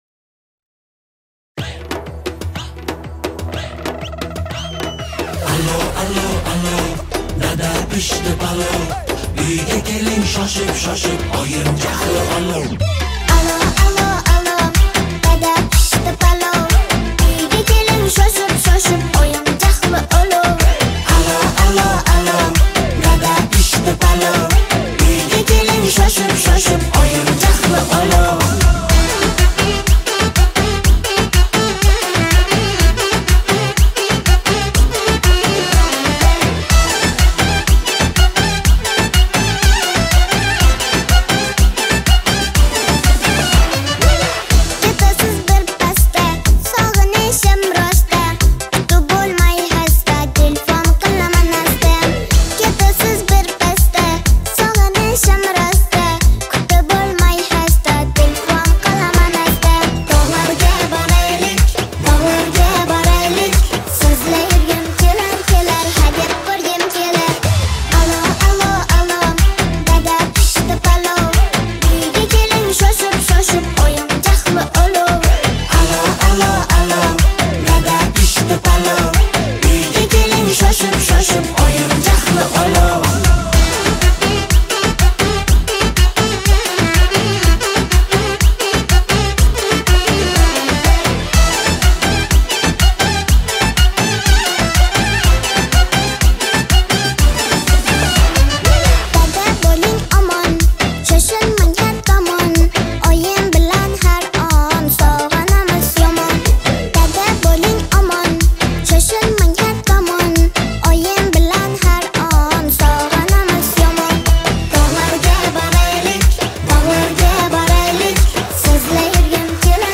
• Узбекские песни